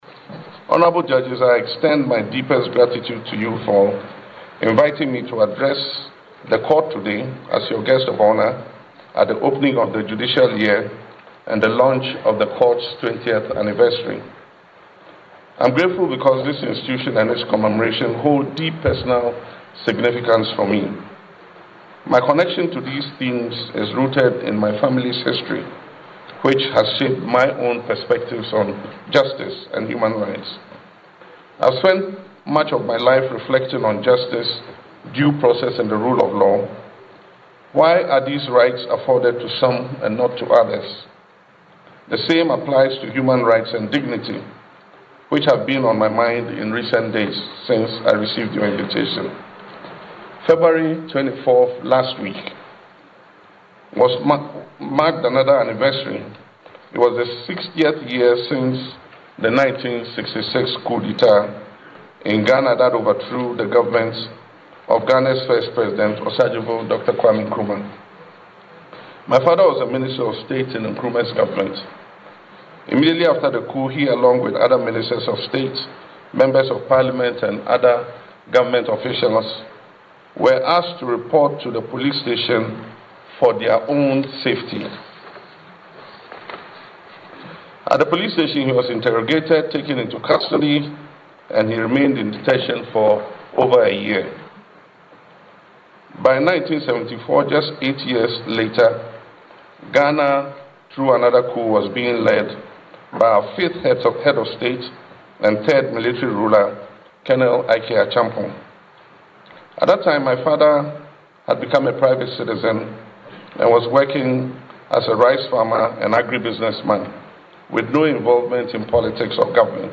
President John Dramani Mahama delivered a deeply personal and emotional address, recounting his father’s detention under successive military regimes in Ghana and explaining how those painful experiences shaped his enduring commitment to justice, human dignity, and the rule of law.